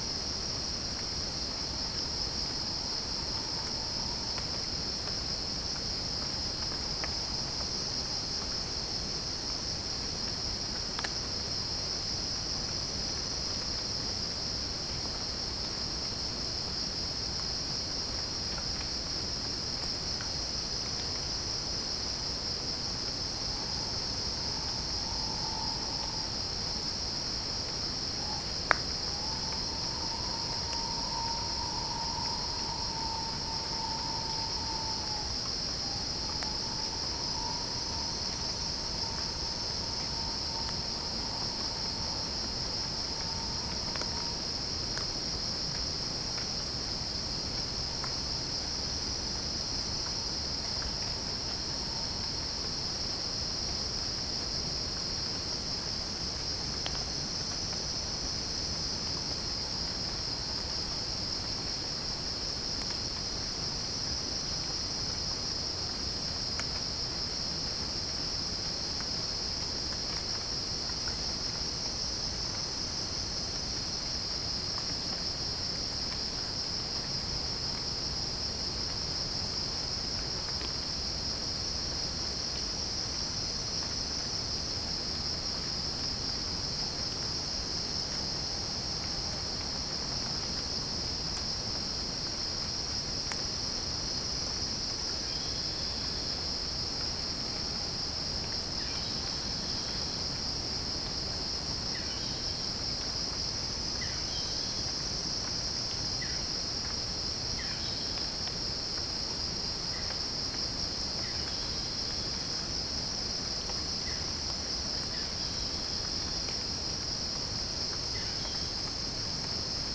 Geopelia striata
Halcyon smyrnensis
Pycnonotus goiavier
Orthotomus ruficeps
Pycnonotus aurigaster
Dicaeum trigonostigma